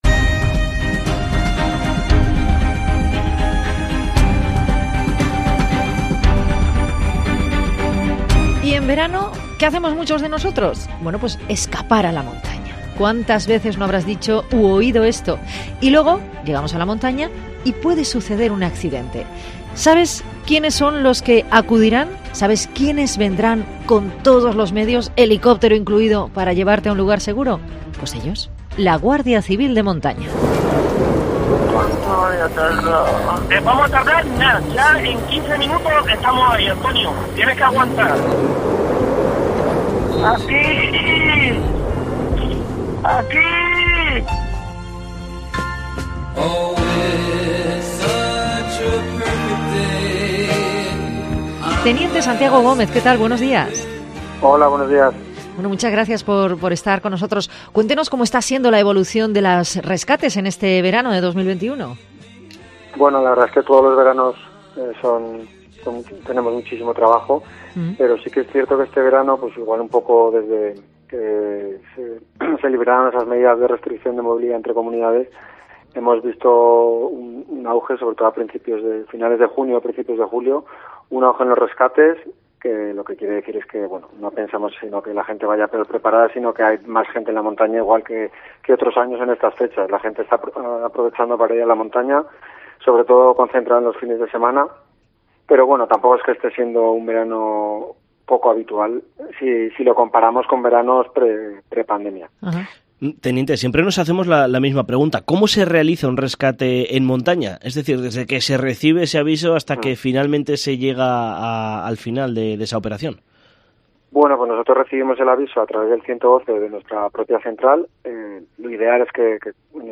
Guardia Civil de Montaña, entrevista